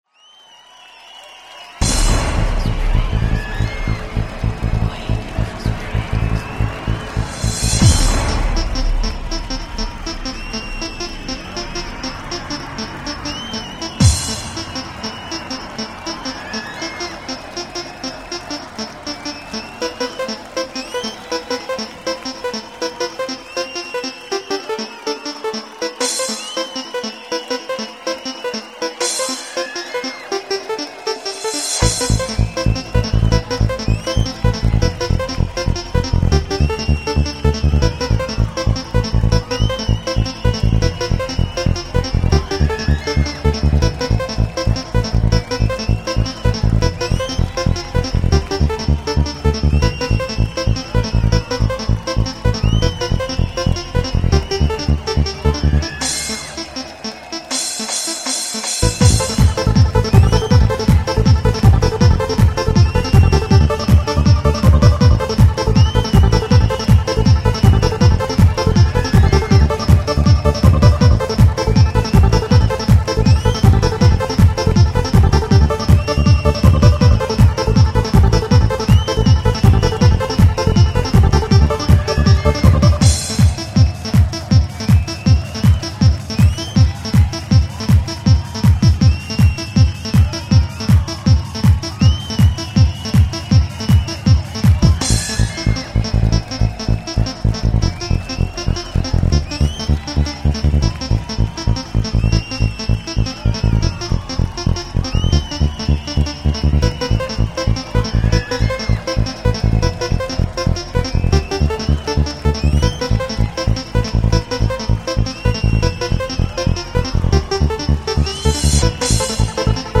• Jakość: 44kHz, Stereo